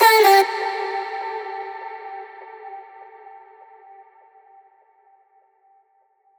VR_vox_hit_dunno_Dmin.wav